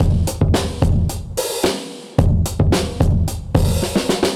Index of /musicradar/dusty-funk-samples/Beats/110bpm/Alt Sound
DF_BeatA[dustier]_110-03.wav